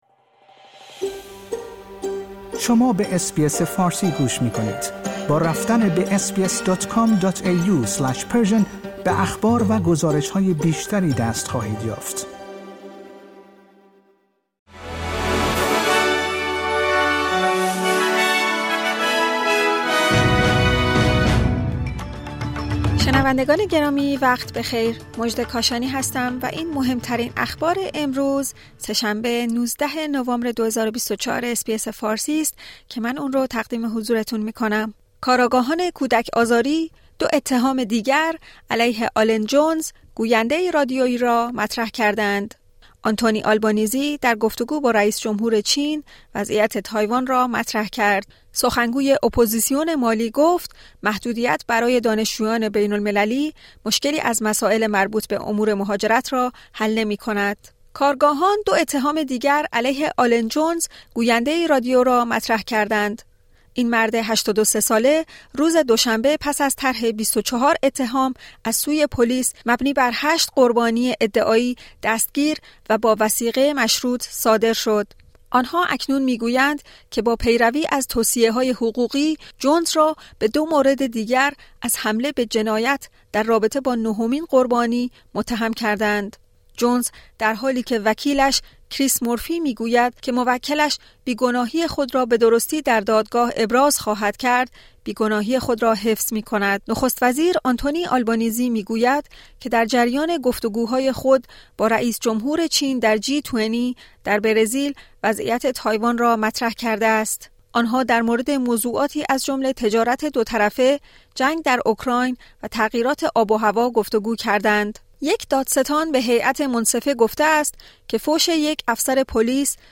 در این پادکست خبری مهمترین اخبار استرالیا در روز سه‌شنبه ۱۹ نوامبر ۲۰۲۴ ارائه شده است.